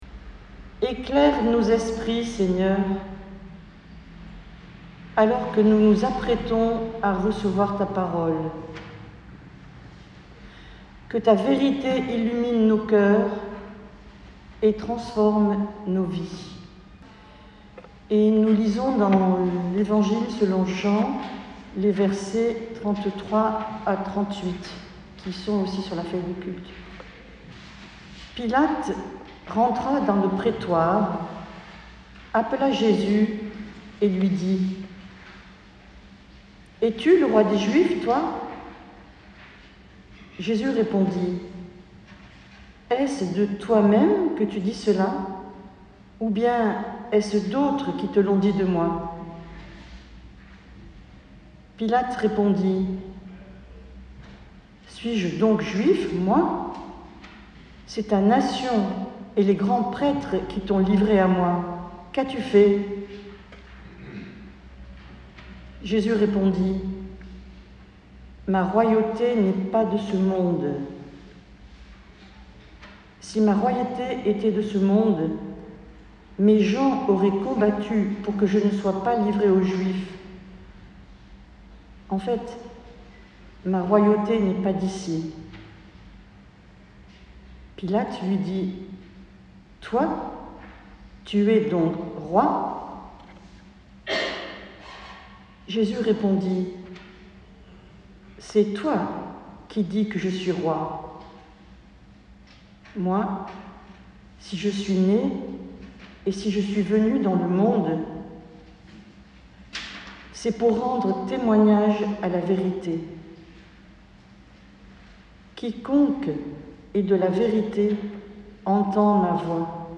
Prédication du 24 nov 2024.mp3 (31.12 Mo)